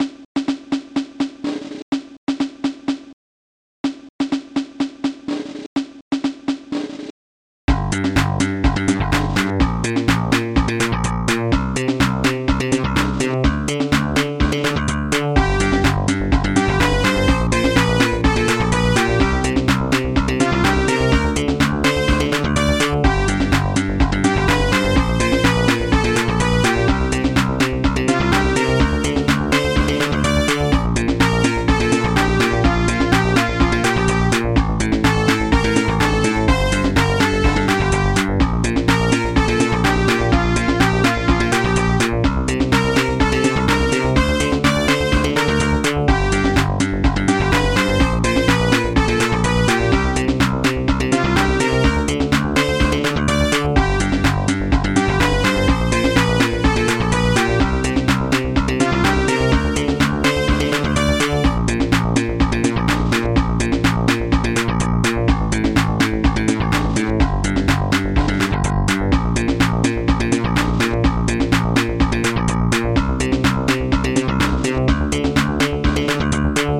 Protracker Module
title music